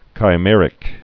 (kī-mĕrĭk, -mîr-)